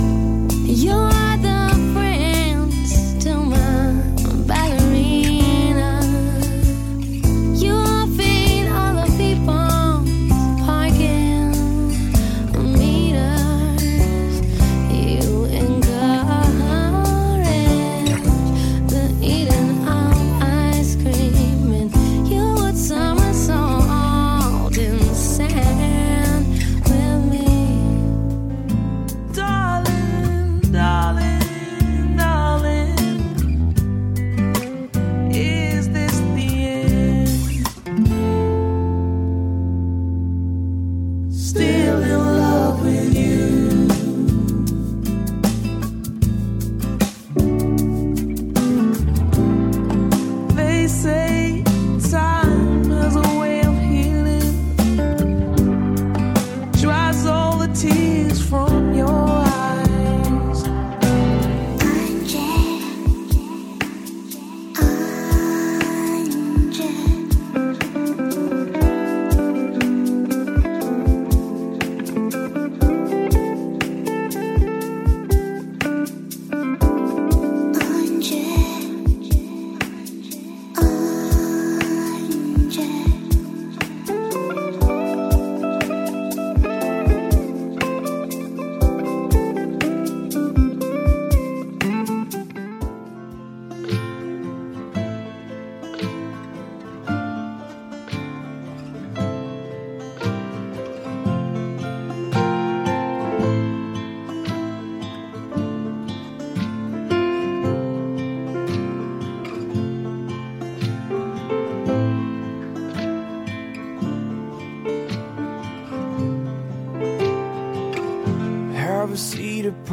Relaxing Songs
Mellow Songs for a Relaxing Atmosphere